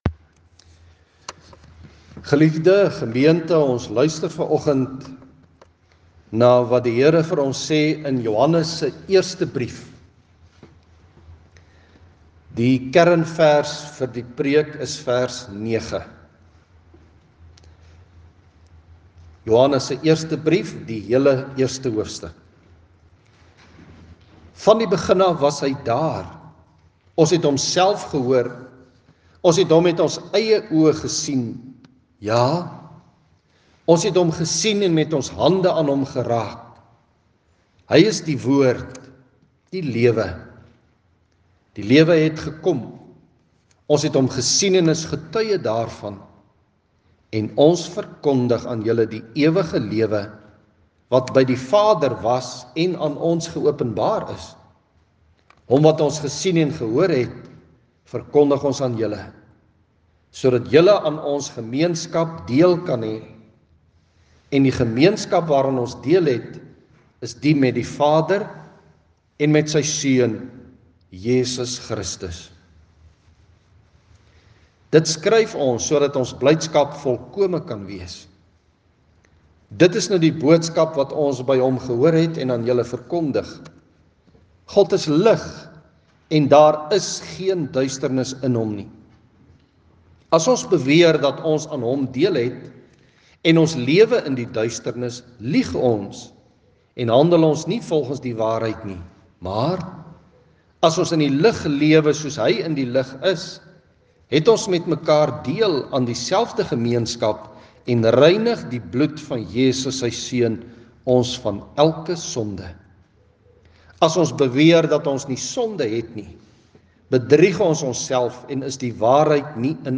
Om te keer dat nagmaal ‘n gewoonte by my word, het ek nodig om my goed voor te berei. Vanoggend se erediens gaan juis oor voorbereiding vir nagmaal.